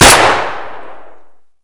shoot.ogg